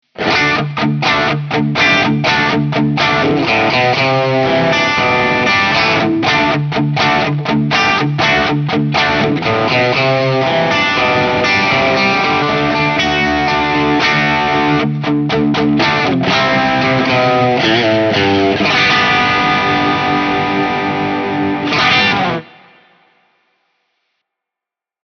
Tutte le clip audio sono state registrate con amplificatore Fender Deluxe e una cassa 2×12 con altoparlanti Celestion Creamback 75.
Clip 3 – Stratocaster Max Gain, clean amp
Chitarra: Fender Stratocaster (pickup al ponte)
Over Drive: 10/10